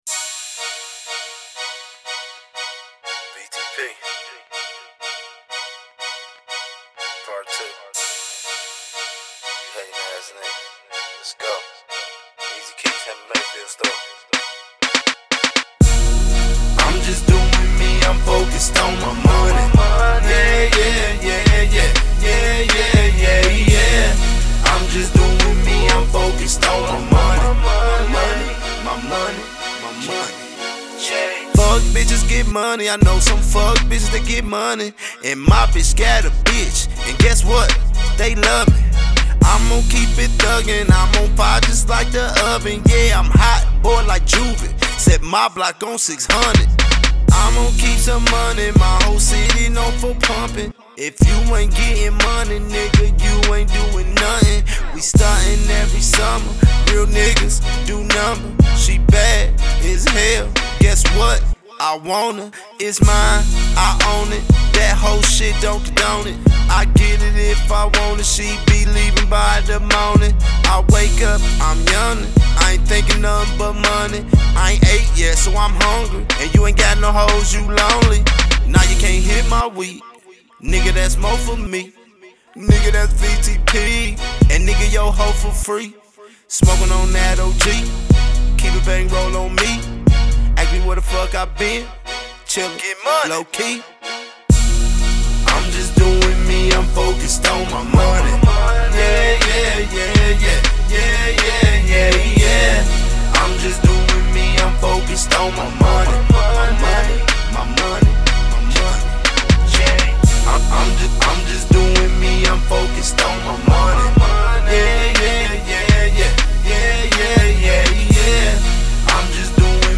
Description : Reality Rap out of the Midwest!